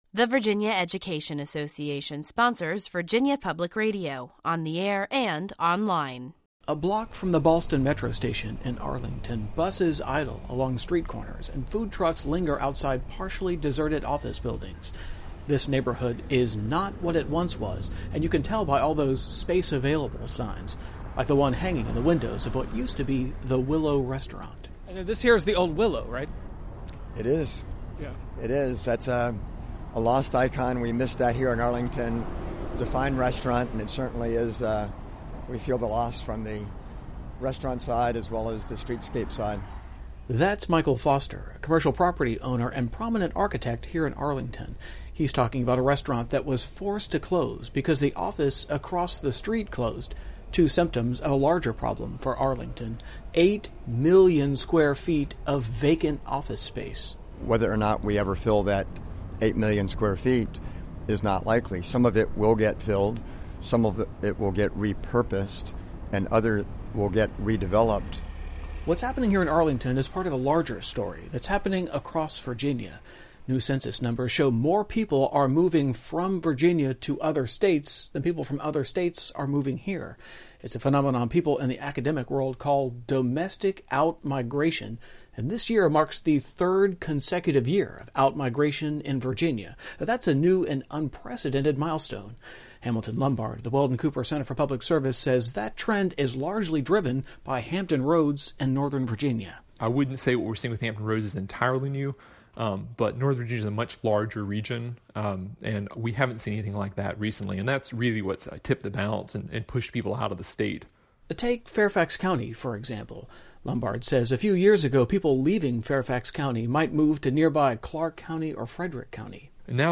vpr-outmigration-feature.mp3